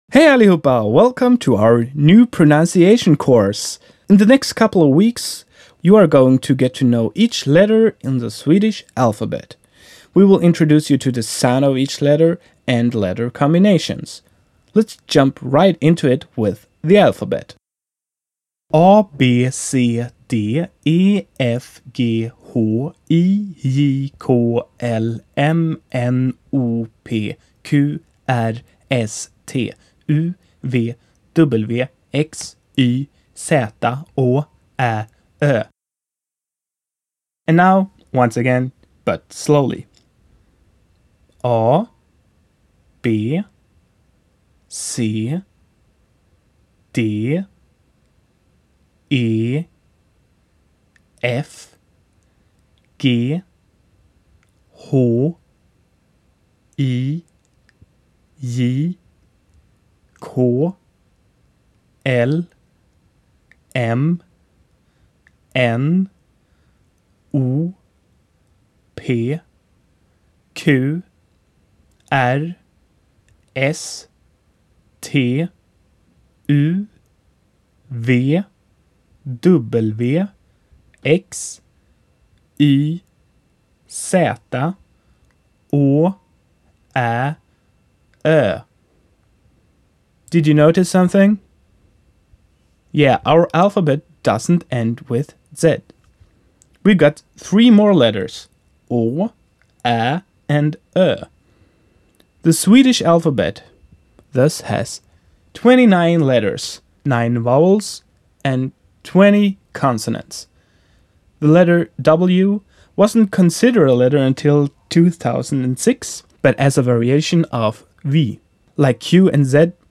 Pronunciation #1 - The alphabet
• when they are emphasized and no consonant follows (bi [biː] bee)